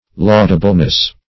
Laudableness \Laud"a*ble*ness\ (l[add]d"[.a]*b'l*n[e^]s), n.